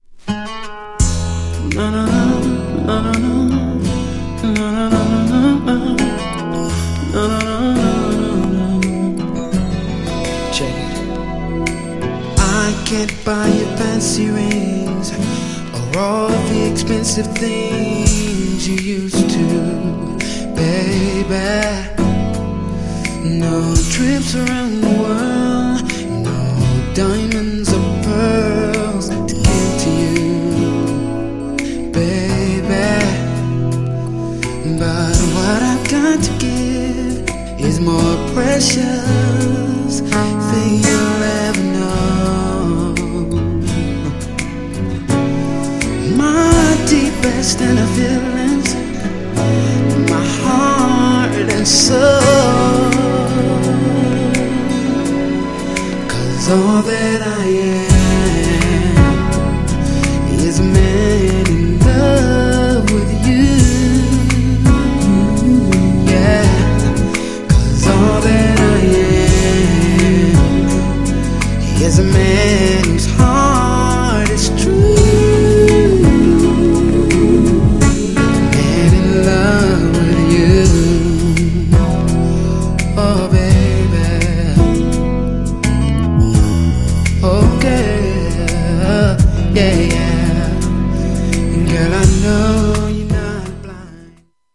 GENRE R&B
BPM 66〜70BPM
# スロー # ハートウォームナンバー
# メロウR&B
男性VOCAL_R&B